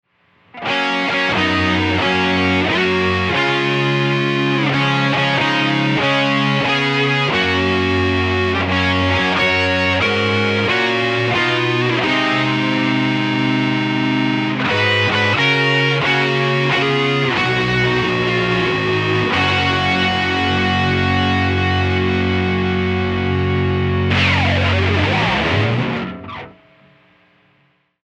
metal-birthday.mp3